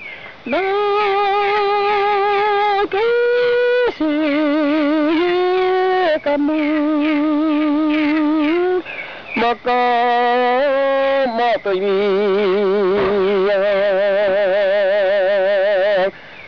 When I was on the jungle island of Siberut for my 30th birthday a medicine man